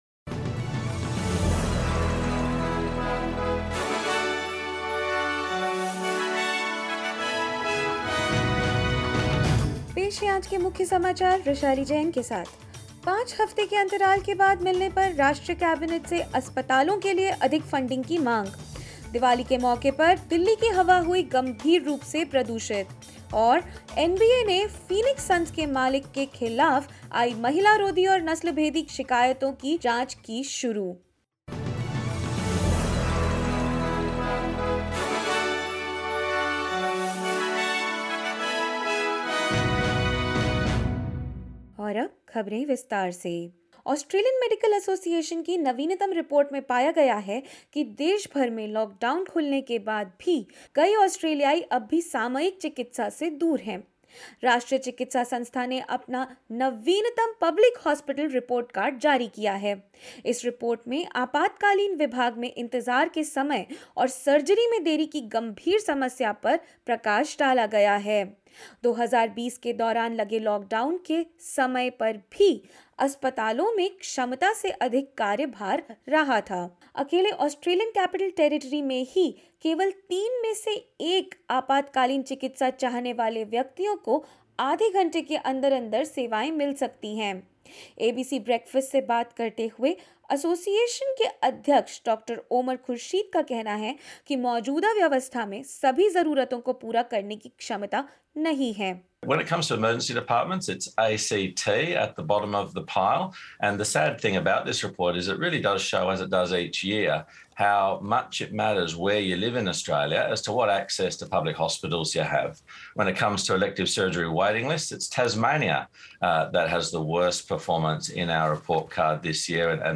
In this latest SBS Hindi news bulletin of Australia and India: NSW-Victoria resume restriction-free travel for vaccinated people; Calls for increased hospital funding as National Cabinet meets for the first time in five weeks and more.